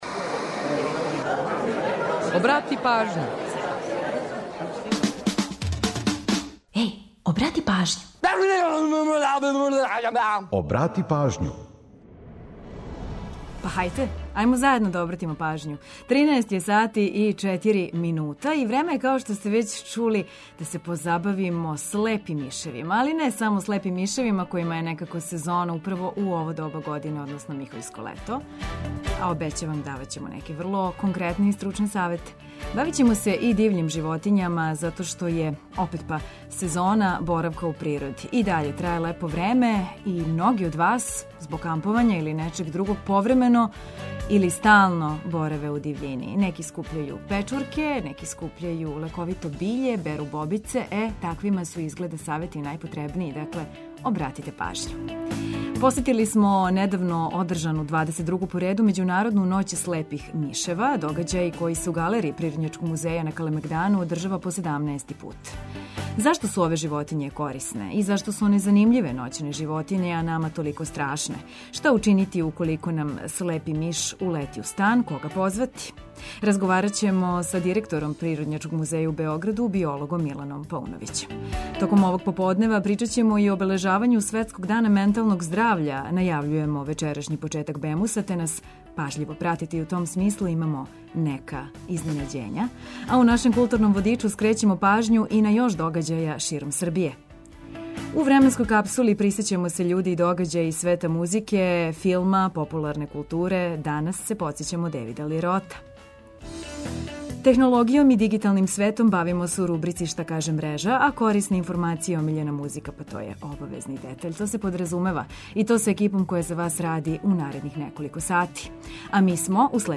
Технологијом и дигиталним светом бавићемо се у рубрици „Шта каже мрежа”, а корисне информације и омиљена музика су обавезни детаљ.